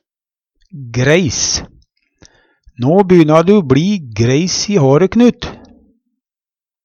greis - Numedalsmål (en-US)